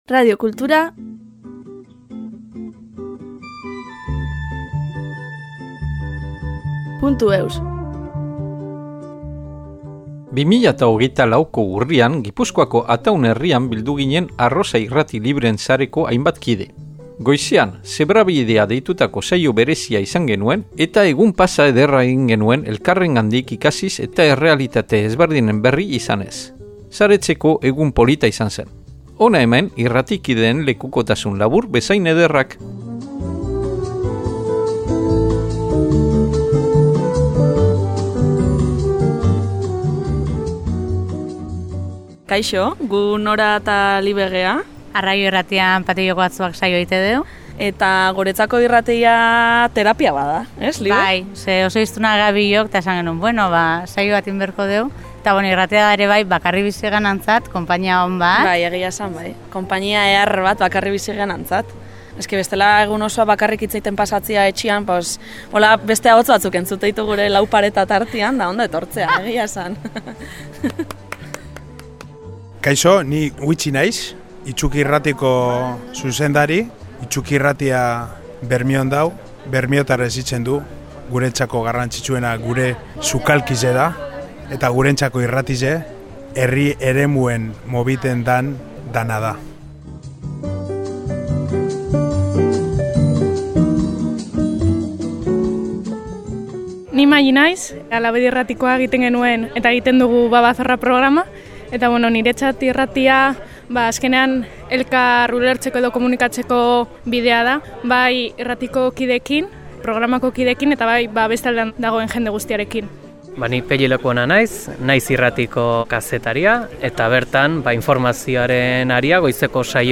2024ko urrian, Gipuzkoako Ataun herrian bildu ginen « Arrosa Irrati Libreen sareko » hainbat kide.
Hona hemen irrati kideen lekukotasun labur bezain ederrak !